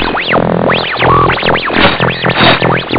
Radio Error 3 Sound Effect Free Download